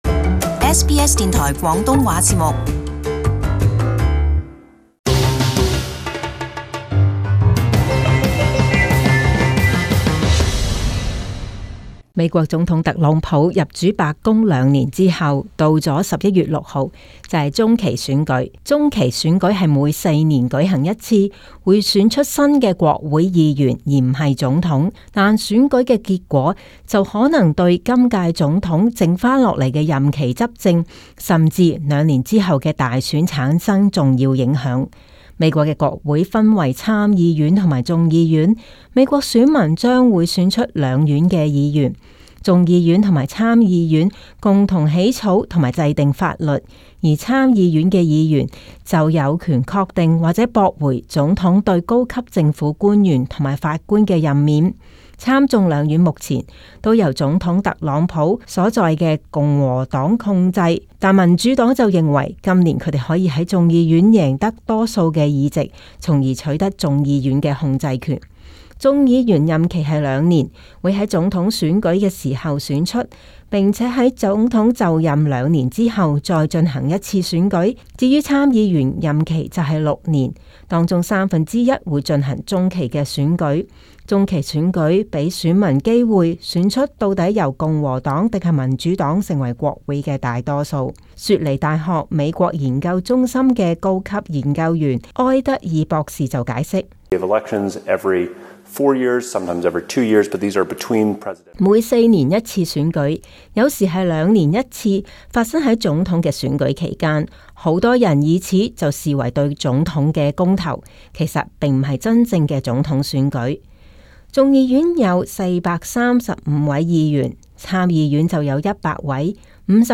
【時事報導】美國中期選舉在即